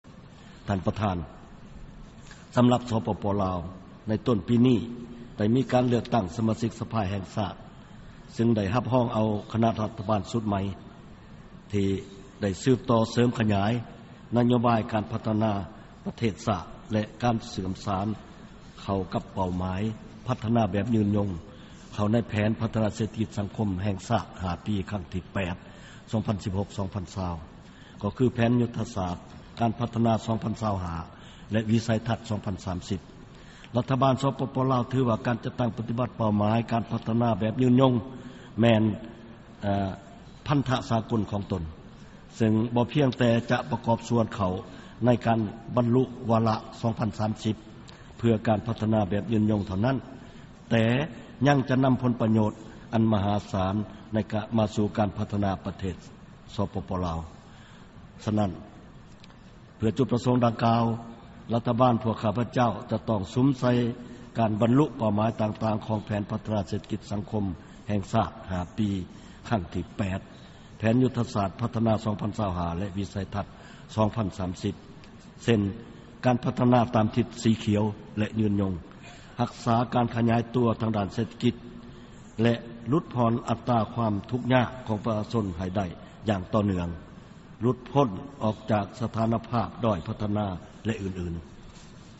ເຊີນຟັງຄຳປາໄສຂອງ ນາຍົກລັດຖະມົນຕີ ສປປ ລາວ ພະນະທ່ານທອງລຸນ ສີສຸລິດ 6